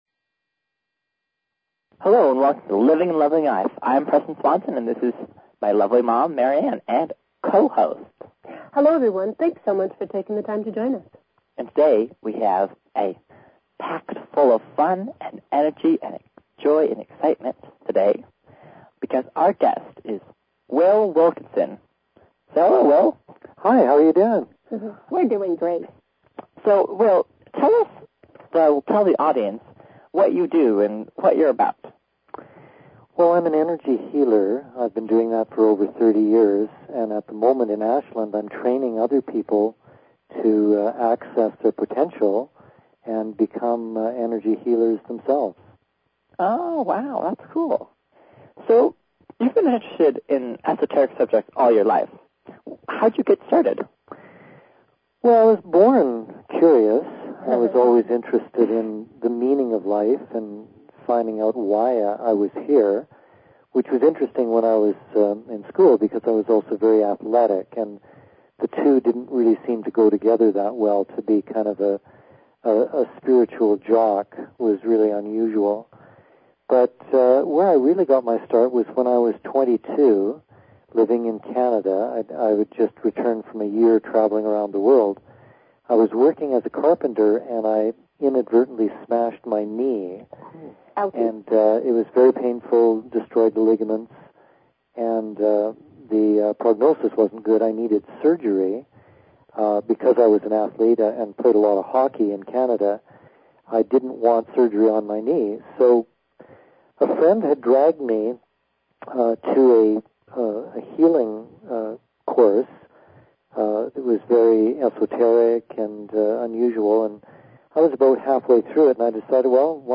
Talk Show Episode, Audio Podcast, Living_and_Loving_Life and Courtesy of BBS Radio on , show guests , about , categorized as